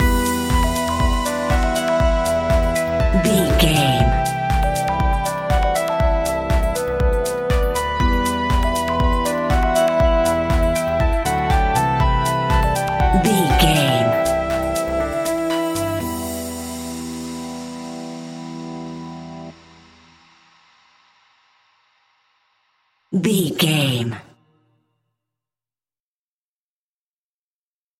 Aeolian/Minor
uplifting
futuristic
energetic
repetitive
bouncy
synthesiser
drum machine
electro house
progressive house
synth leads
synth bass